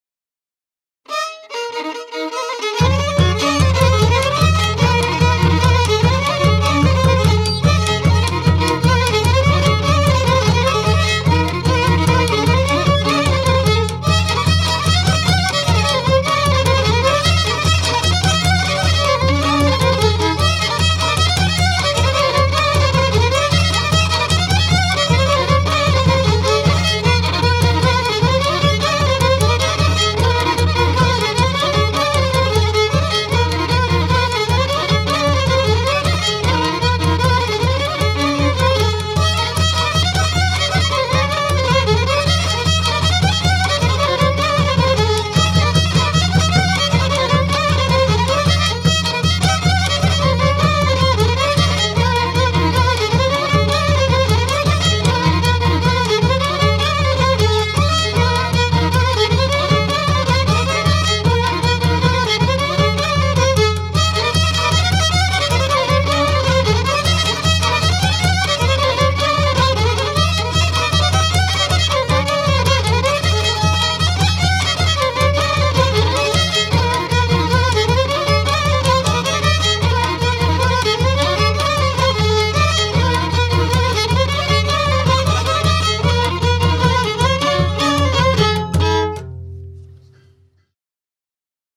hegedű
Csík vm. gardon